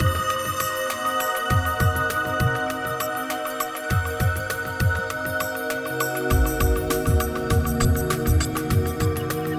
DeepPercussiveAtmo3_100_C.wav